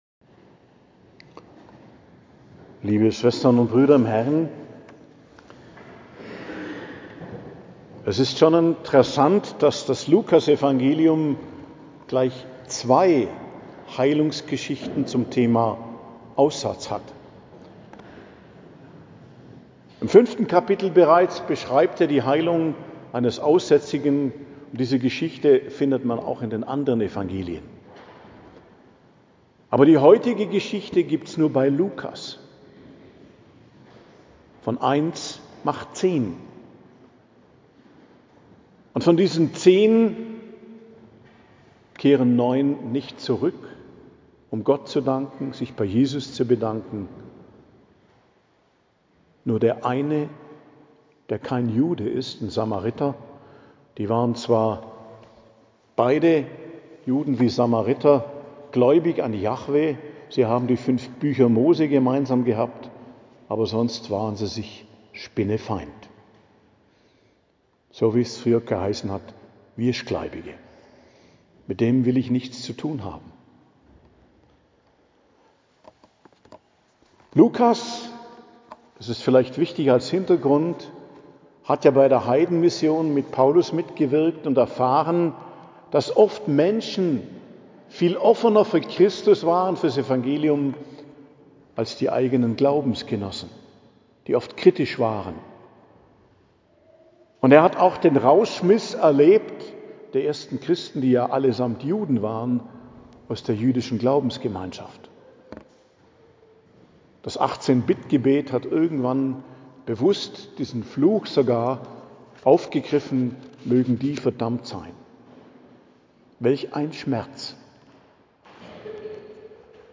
Predigt zum 28. Sonntag i.J., 12.10.2025 ~ Geistliches Zentrum Kloster Heiligkreuztal Podcast